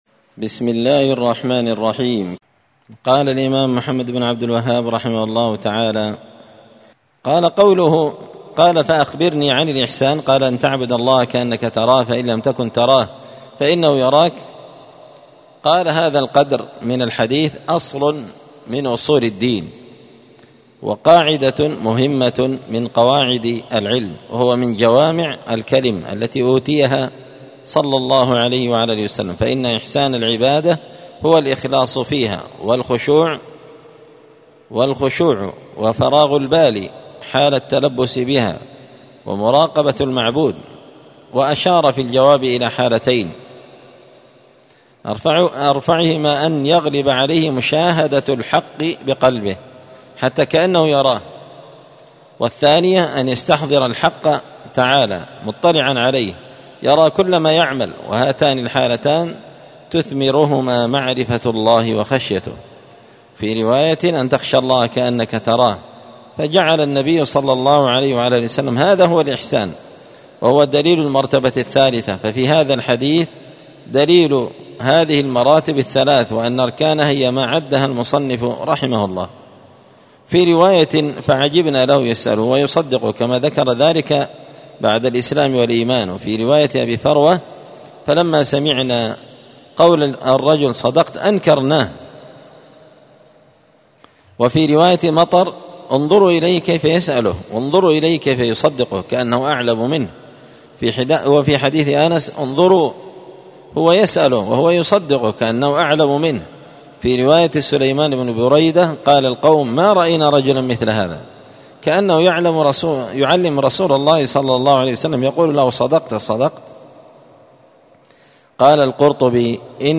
دار الحديث السلفية بمسجد الفرقان بقشن المهرة اليمن
الأربعاء 7 جمادى الآخرة 1445 هــــ | الدروس، حاشية الأصول الثلاثة لابن قاسم الحنبلي، دروس التوحيد و العقيدة | شارك بتعليقك | 65 المشاهدات